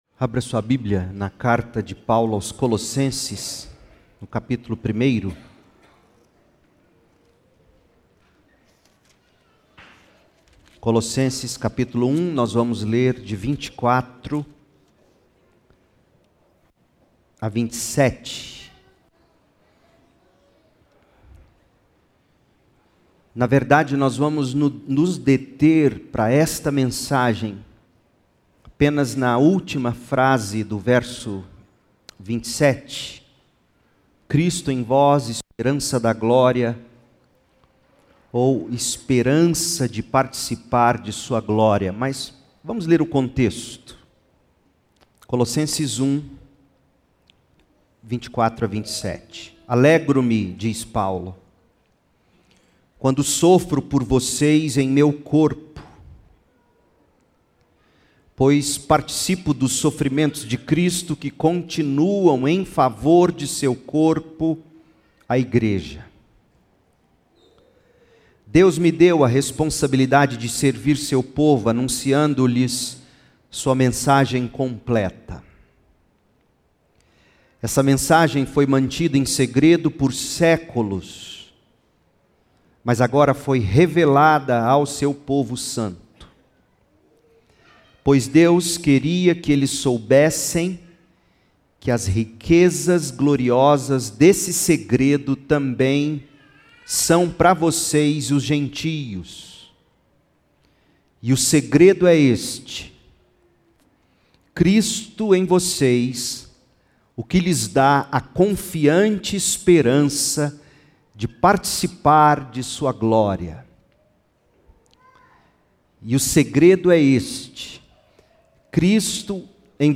A Esperança da Glória - Segunda Igreja Batista em Goiânia
Aliás, todos estamos aqui, neste culto de vigília, nutrindo a esperança de um ano novo melhor.